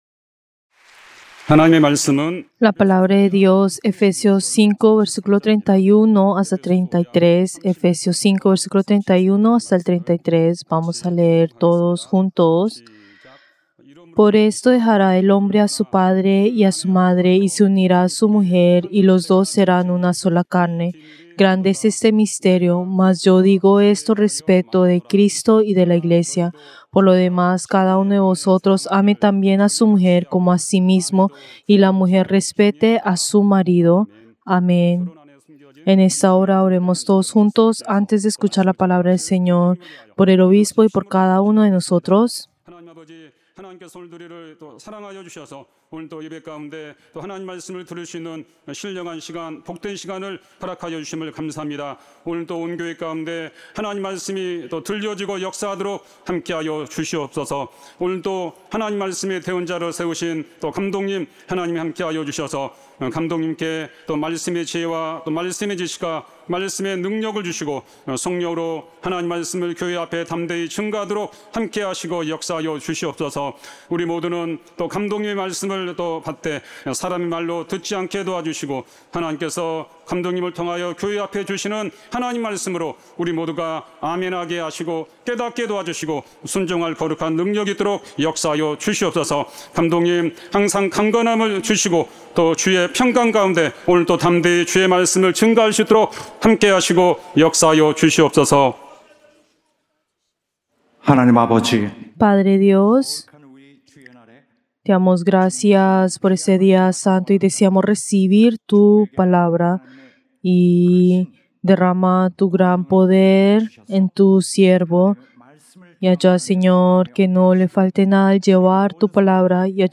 Servicio del Día del Señor del 30 de marzo del 2025